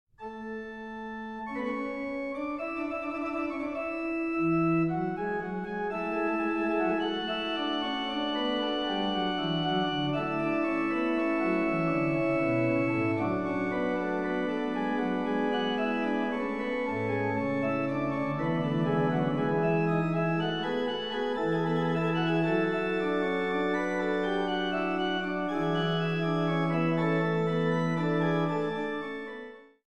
1980 Ahrend organ in Monash University, Melbourne
Organ